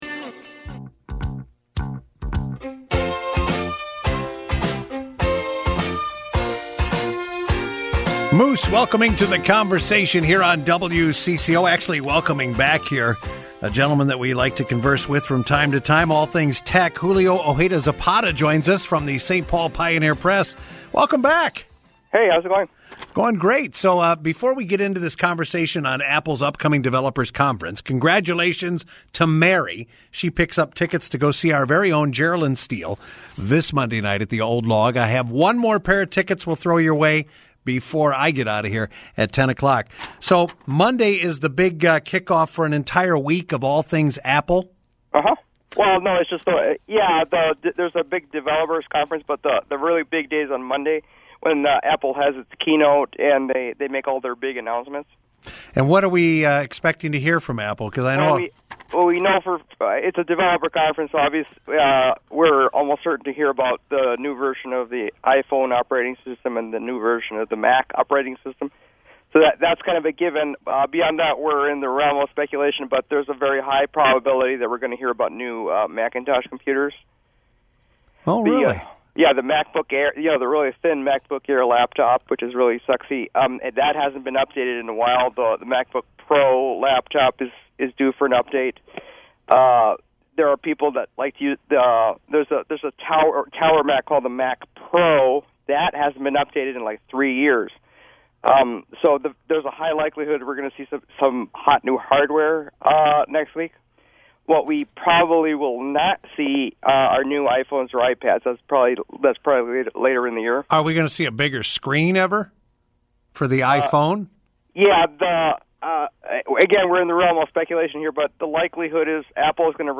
I talk Apple iRadio on WCCO-AM